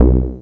05 Synther 5 B.wav